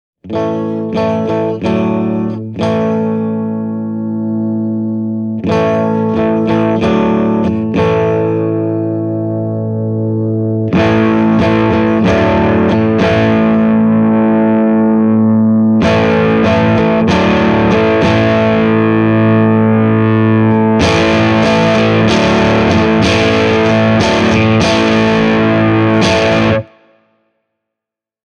Tästä pätkästä saa hyvän idean AC30S1:n soundimaailmasta. Gain on säädetty täysille ja vahvistimen säröä ohjataan Fender Stratocasterin volume-säätimellä: